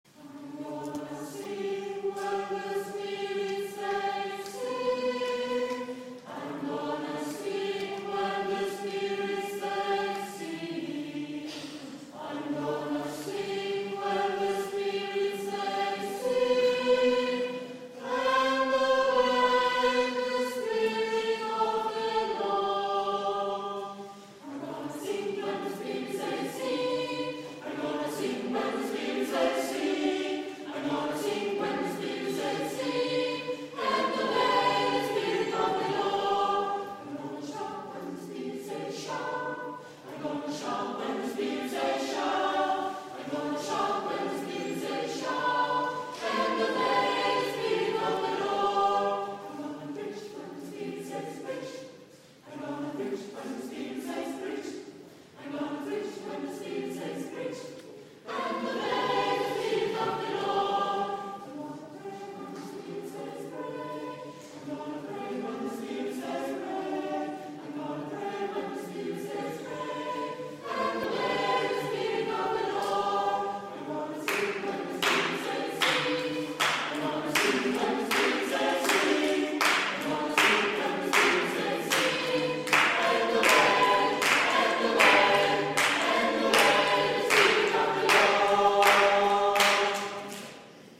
Negro Spiritual